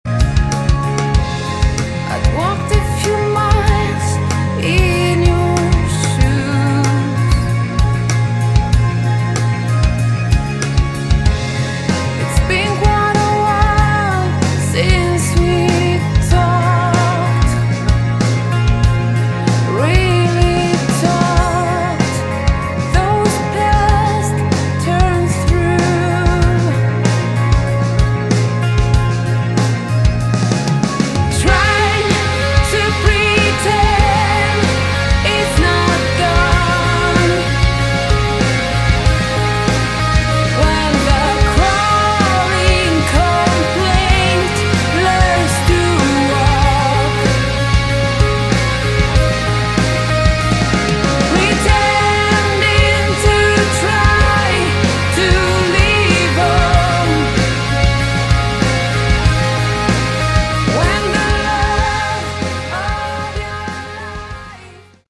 Category: Modern Hard Rock/Metal
vocals
guitars, bass, keyboards